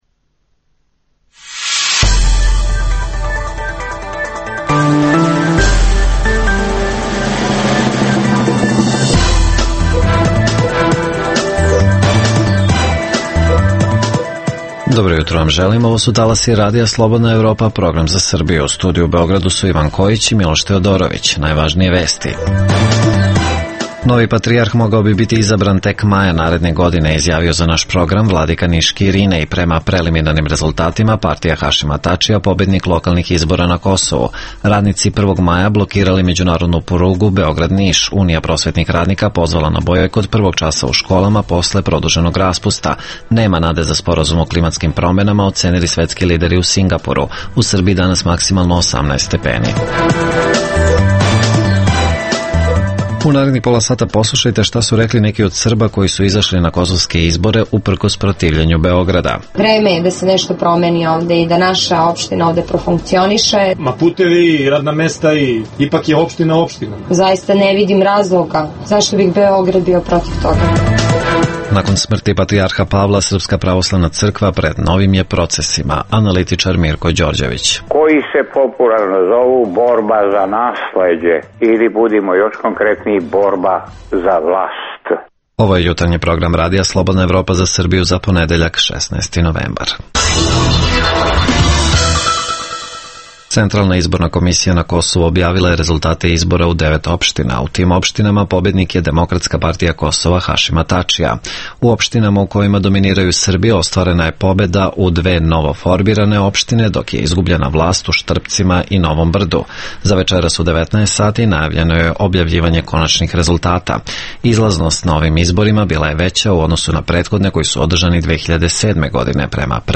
Moguće je da će naslednik preminulog patrijarha Pavla biti izabran tek na prvoj redovnoj sednici Sabora SPC u maju naredne godine. U intervjuu za naš program to je rekao jedan od članova Sinoda, episkop Irinej. On govori i o pripremama za sahranu poglavara SPC, kao i o mogućim kandidatima za novog patrijarha.